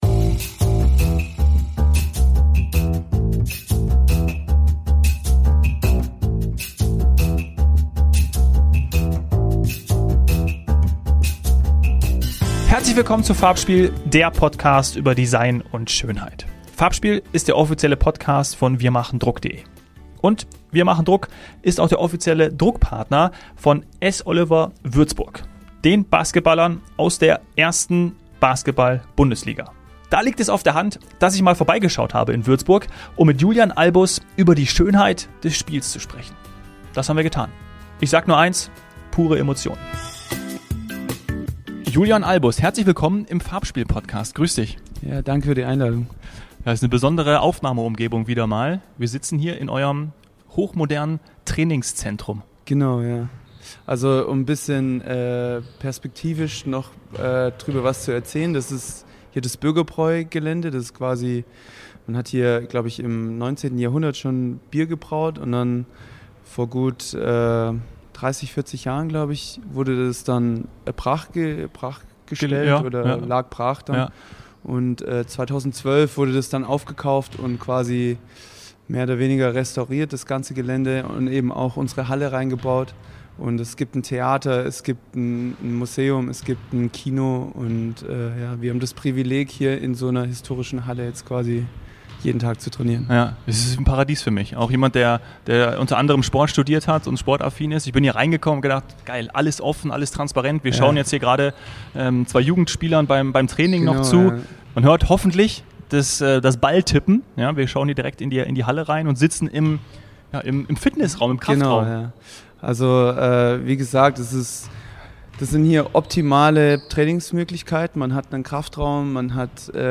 Basketballprofi